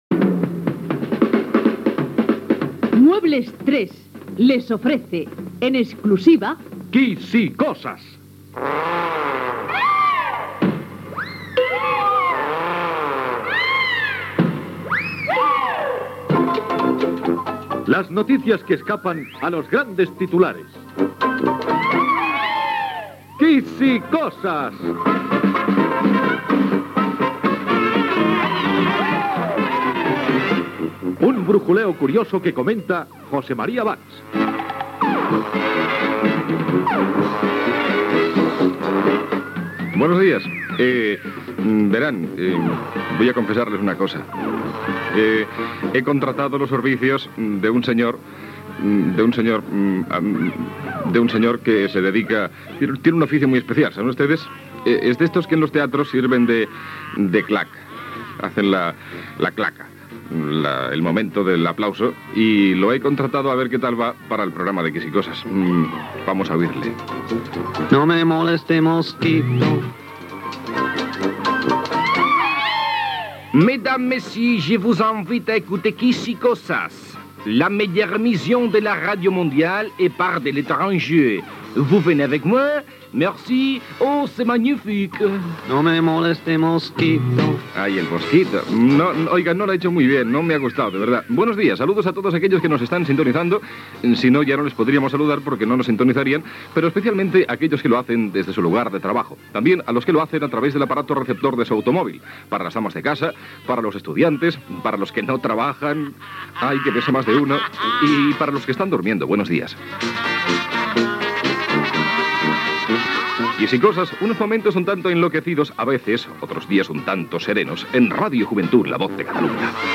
Careta del programa, presentació, el programa ha contractat un col·laborador per fer "la pilota", comentari sobre la Torre Eiffel de París i tema musical per encertar
Entreteniment